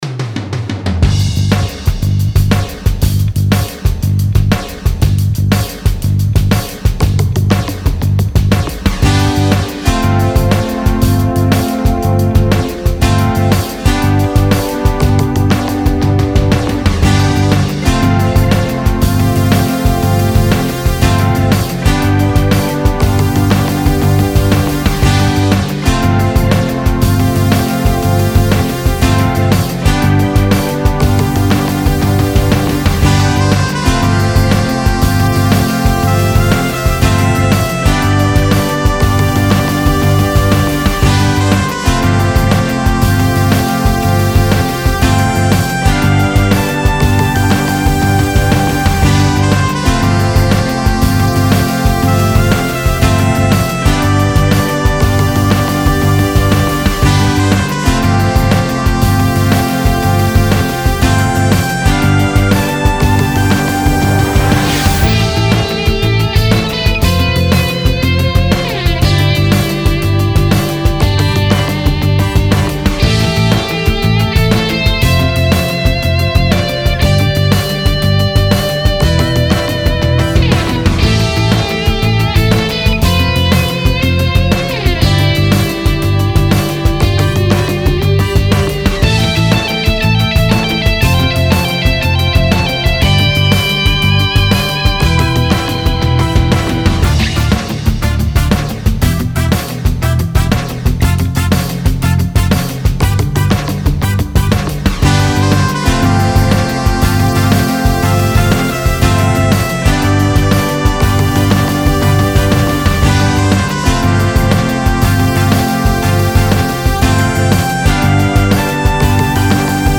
Style Style EDM/Electronic, Oldies, Pop +1 more
Mood Mood Bouncy, Bright, Intense +1 more
Featured Featured Bass, Brass, Drums +2 more
BPM BPM 120